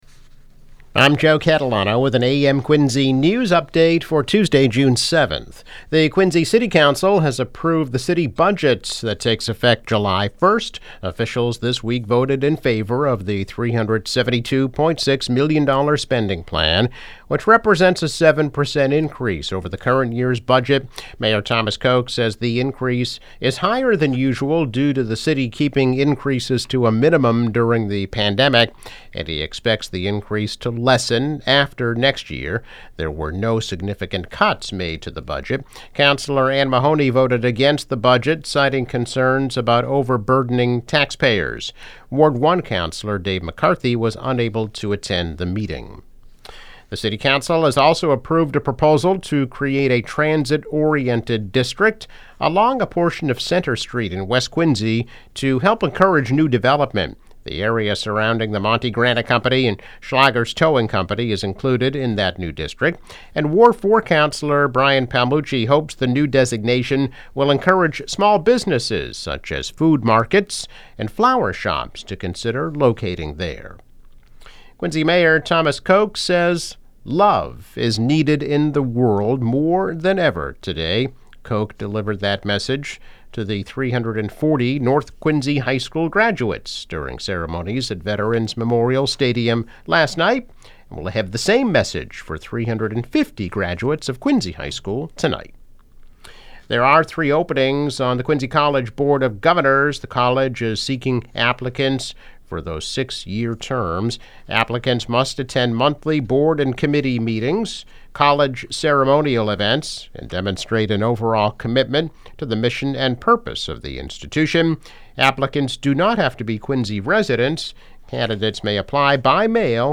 News Update - June 7, 2022